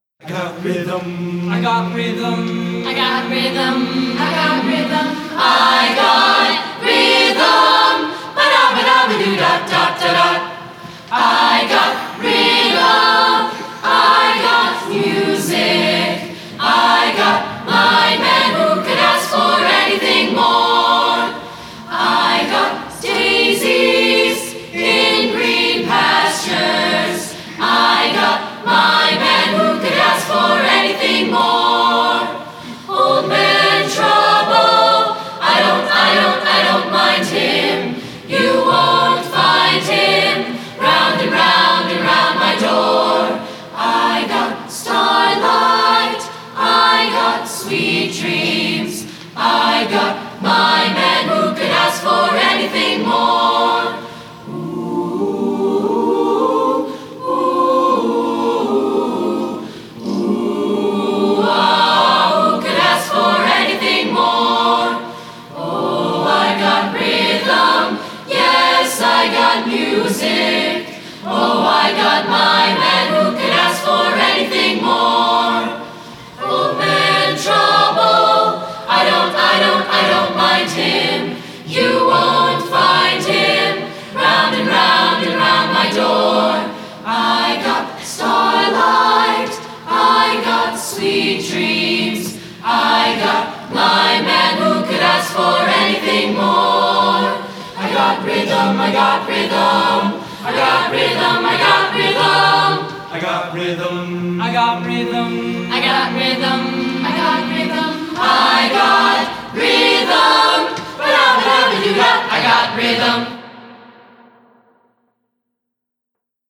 Spring Music Festival
A cappella Choir